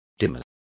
Complete with pronunciation of the translation of demurer.